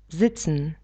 sedet (607x640)sedět sitzen [zicn]